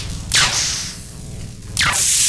blinkeffect.wav